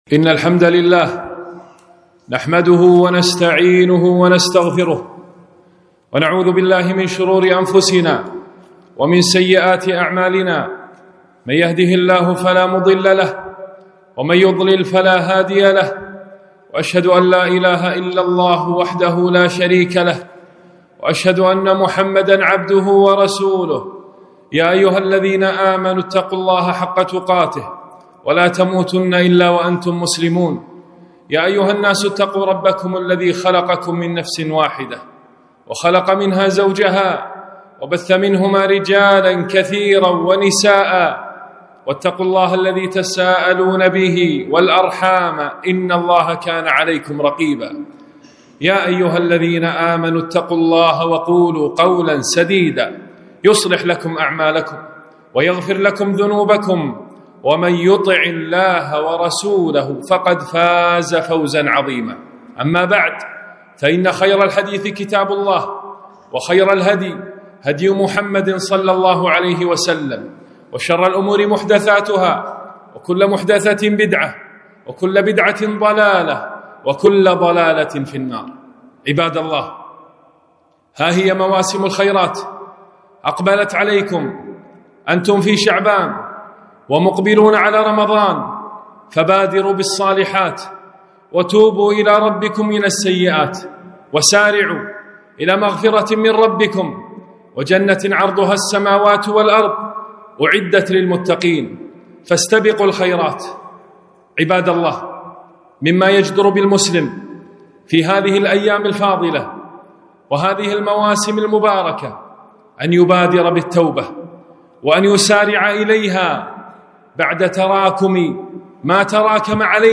خطبة - موسم القرآن